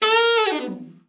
ALT FALL   9.wav